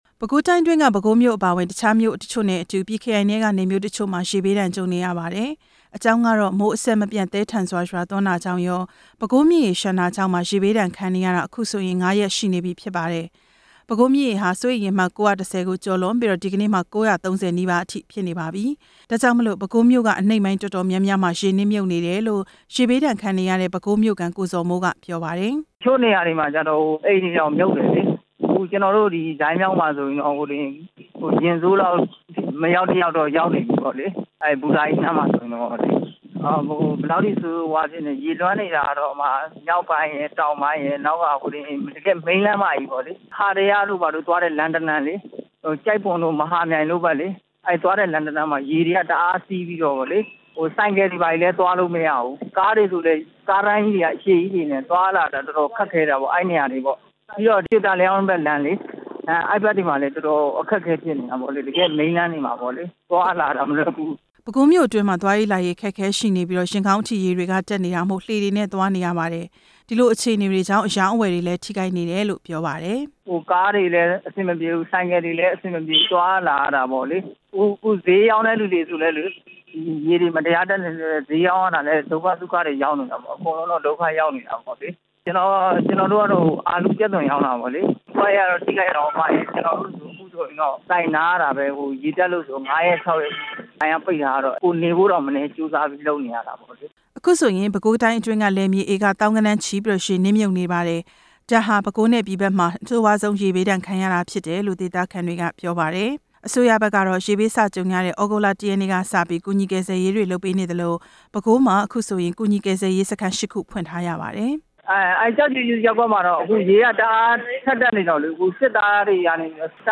ရေဘေးအခြေအနေ တင်ပြချက်